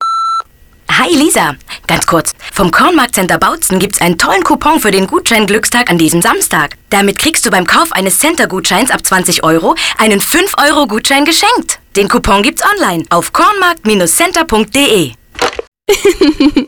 Auswahl Radiowerbespots